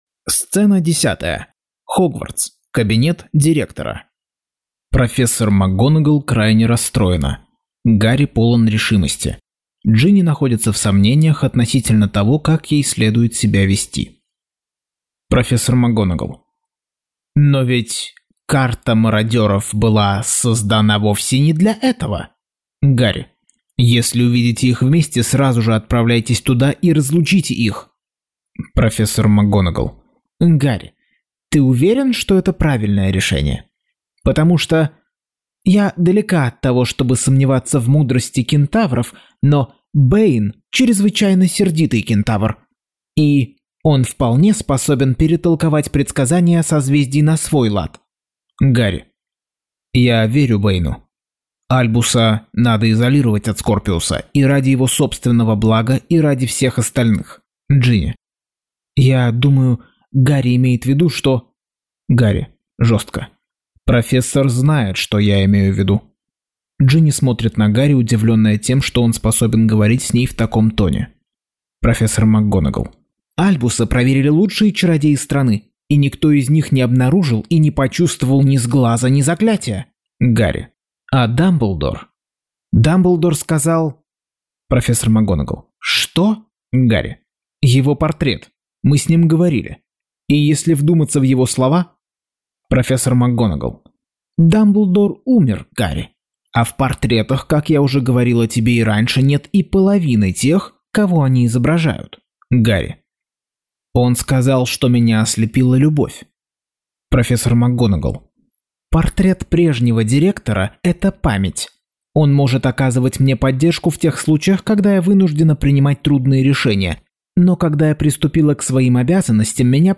Аудиокнига Гарри Поттер и проклятое дитя. Часть 22.